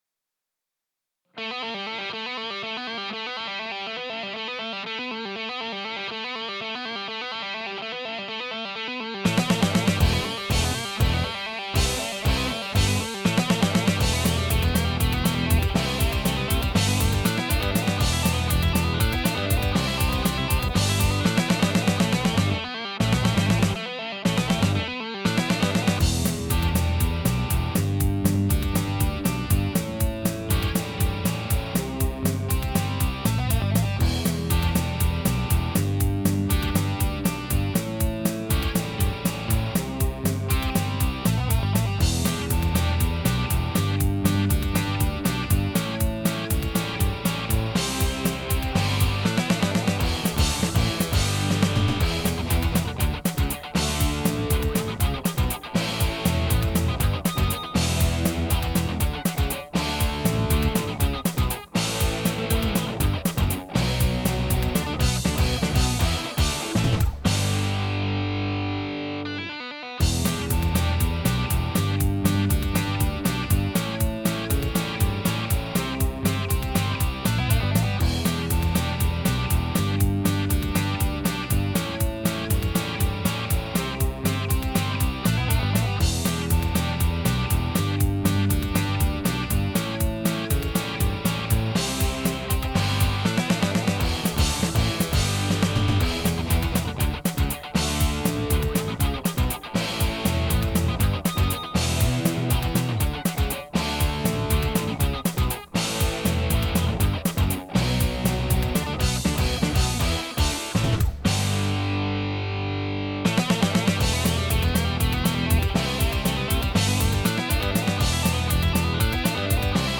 I programmed some drums half-ass for now, so don't worry about that. It's a pretty guitar-driven song.